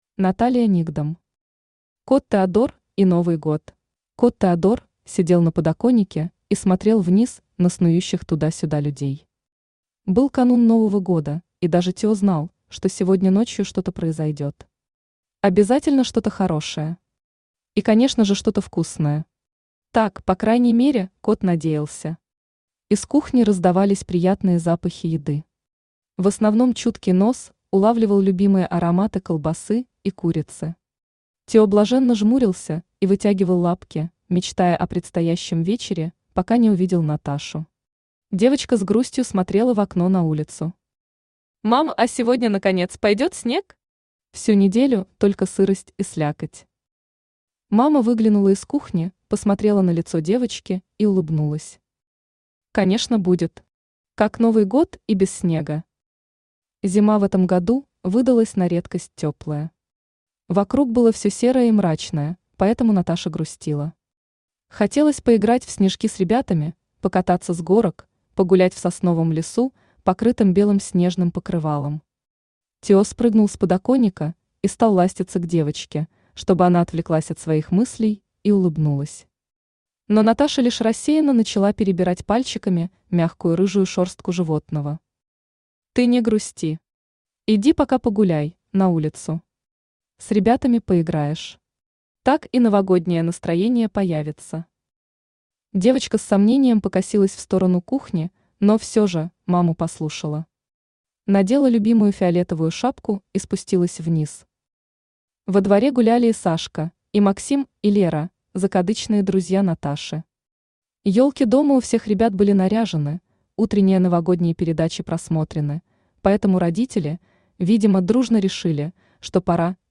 Аудиокнига Кот Теодор и Новый год | Библиотека аудиокниг
Aудиокнига Кот Теодор и Новый год Автор Наталия Сергеевна Никдом Читает аудиокнигу Авточтец ЛитРес.